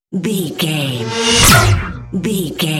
Sci fi pass by shot
Sound Effects
Atonal
futuristic
intense
pass by
vehicle